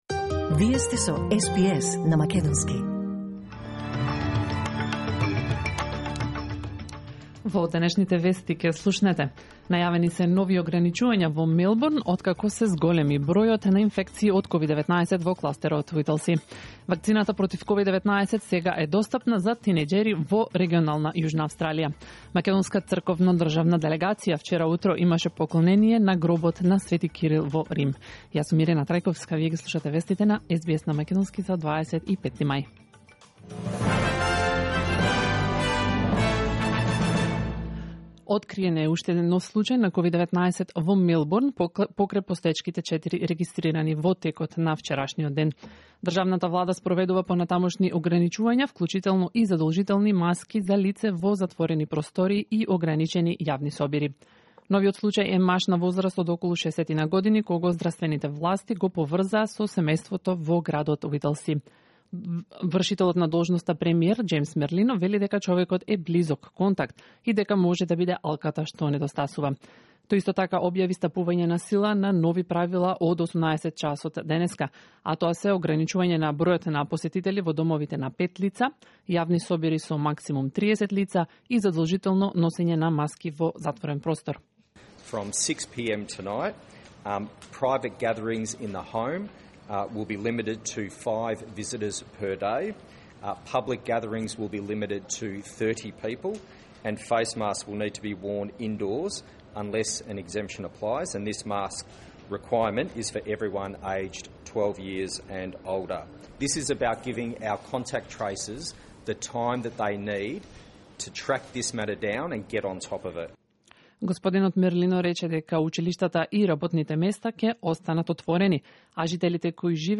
SBS News in Macedonian 25 May 2021